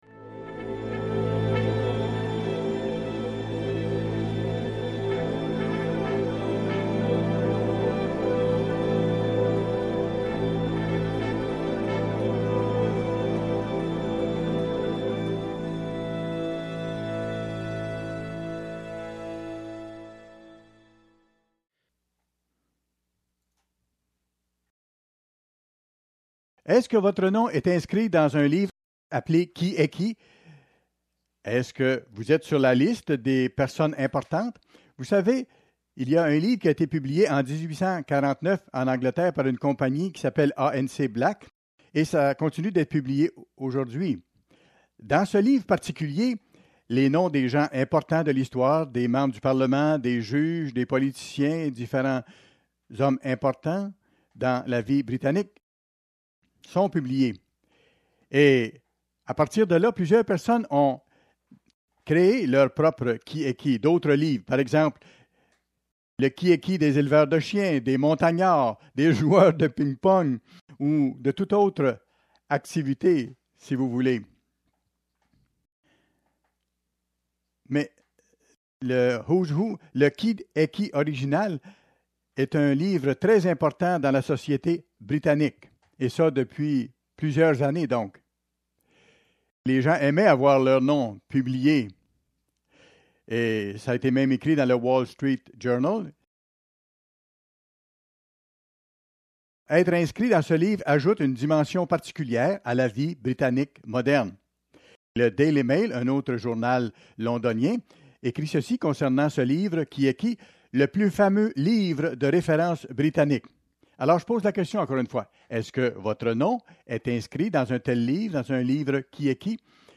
Fête des Tabernacles, 2ème jour Qui est qui dans le Royaume ?